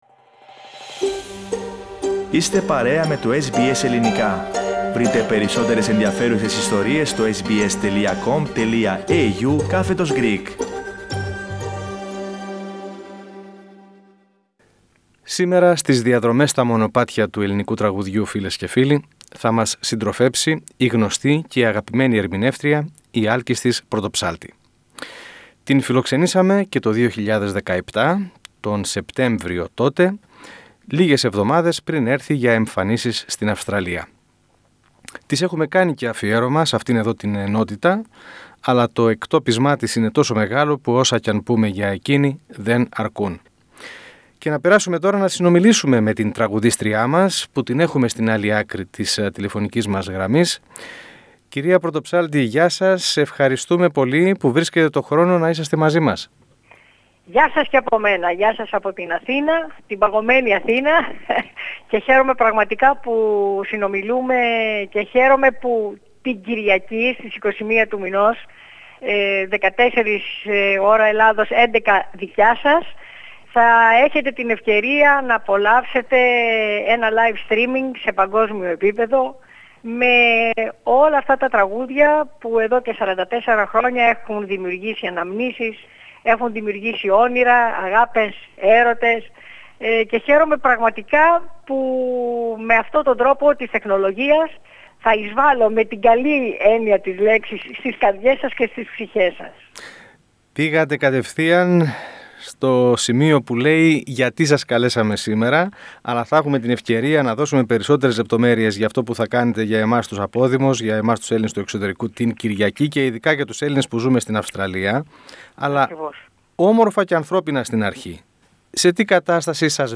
Κατά τη διάρκεια της συνέντευξης, η κ. Πρωτοψάλτη απαντά και για τη δίνη πολιτικής αντιπαράθεσης στην οποία βρέθηκε όταν με πρόταση του δήμου Αθηναίων βγήκε να τραγουδήσει εν μέσω κορωνοϊού στους δρόμους της πρωτεύουσας πάνω σε ένα φορτηγό.